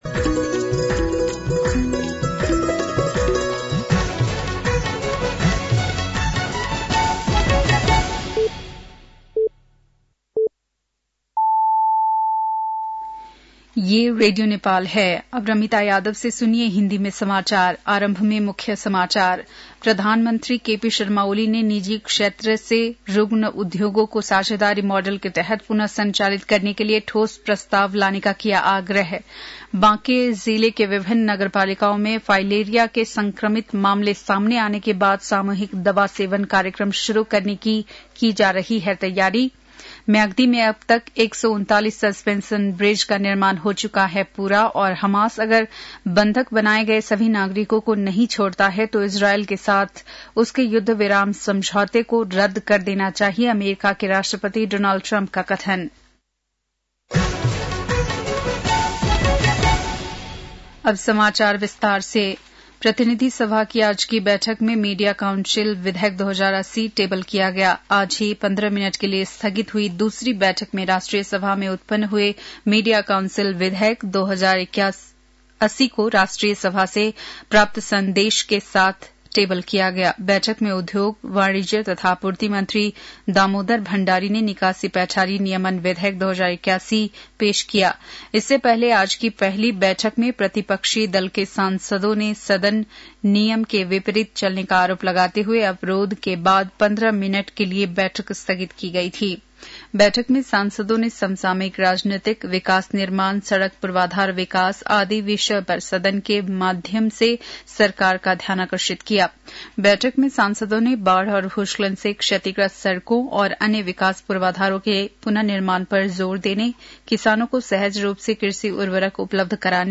बेलुकी १० बजेको हिन्दी समाचार : ३० माघ , २०८१